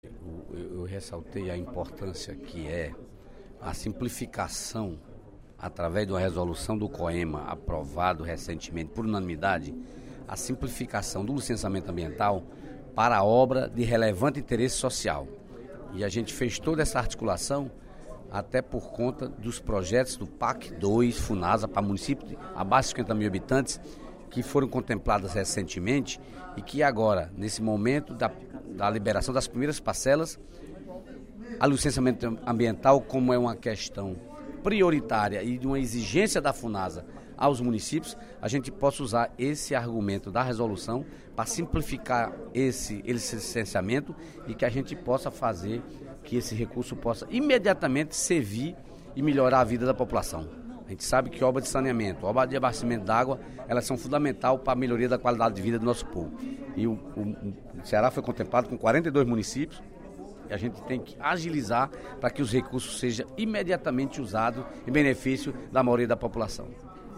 Em pronunciamento no primeiro expediente da sessão plenária da Assembleia Legislativa desta quinta-feira (06/11), o deputado Dedé Teixeira (PT) adiantou que irá sugerir à Mesa Diretora a criação de um conselho para debater a reforma política.